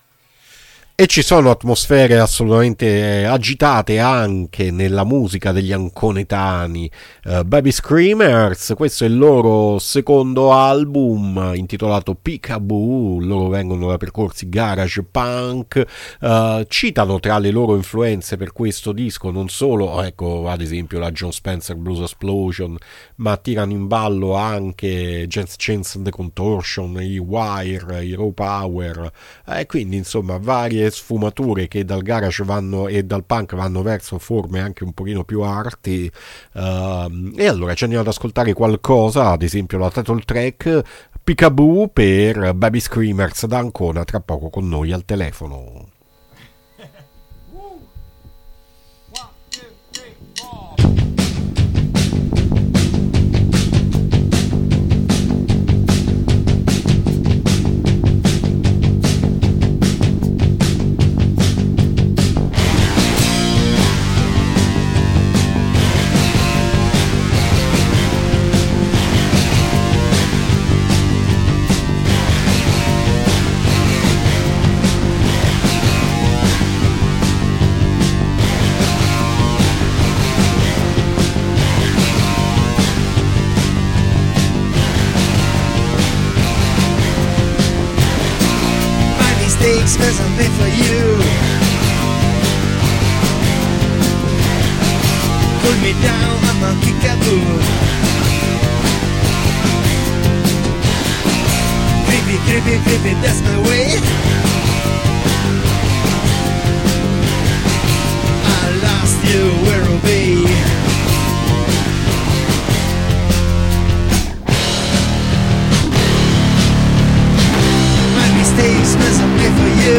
I Babyscreamers sono un trio di Ancona che propone un graffiante garage punk adrenalitico dove non manca anche qualche elemento di agitato pop